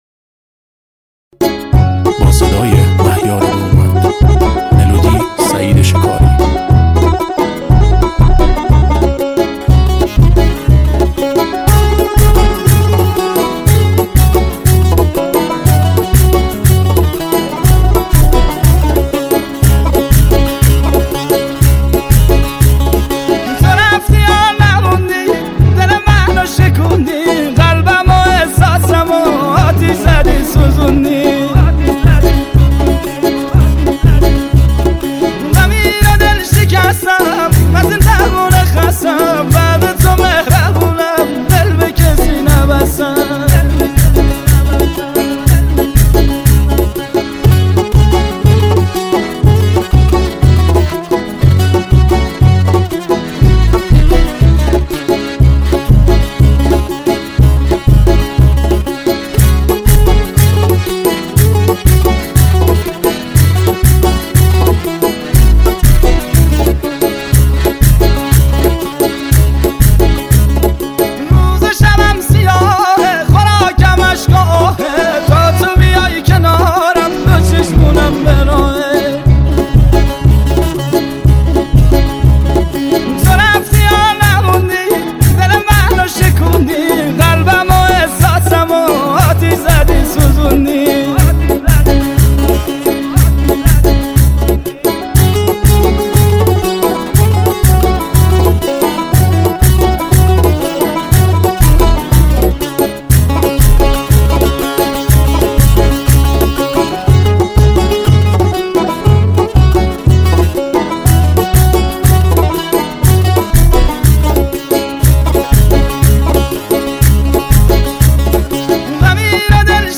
دانلود ریمیکس